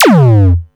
Modular Tom 02.wav